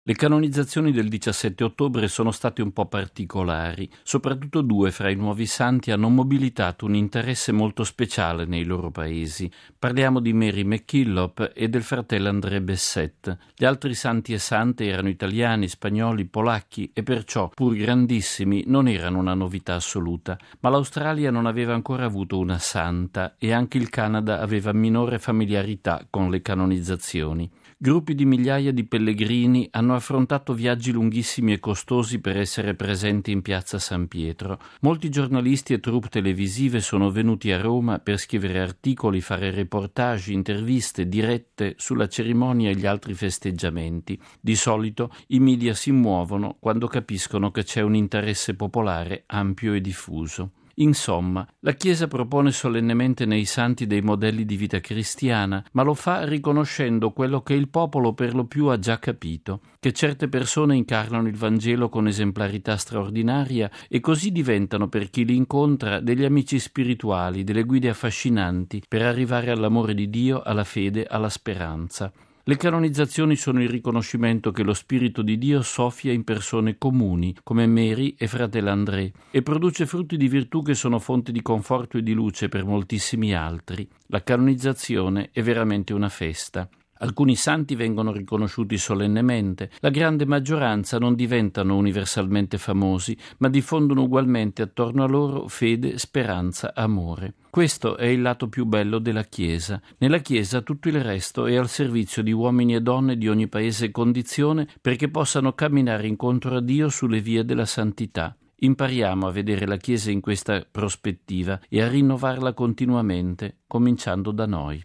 Ascoltiamo in proposito la riflessione del nostro direttore, padre Federico Lombardi, nel suo editoriale per “Octava Dies”, il settimanale informativo del Centro Televisivo Vaticano: